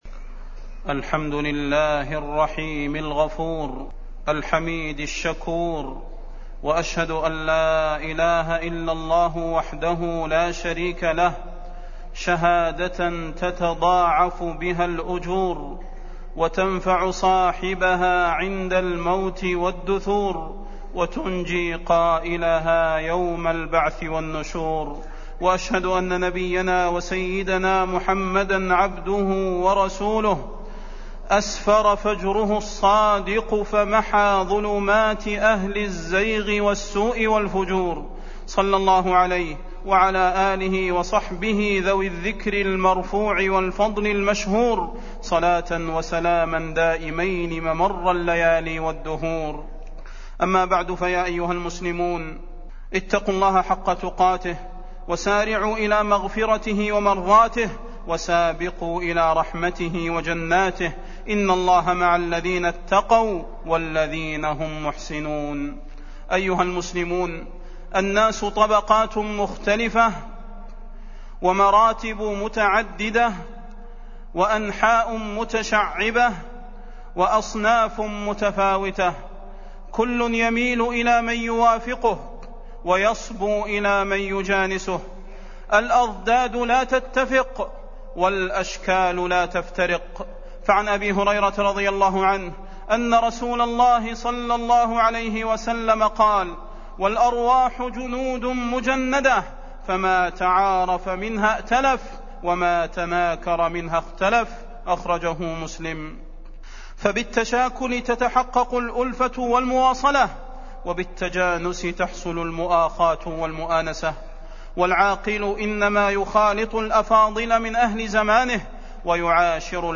تاريخ النشر ٢٩ جمادى الأولى ١٤٢٨ هـ المكان: المسجد النبوي الشيخ: فضيلة الشيخ د. صلاح بن محمد البدير فضيلة الشيخ د. صلاح بن محمد البدير طبقات الناس ومراتبهم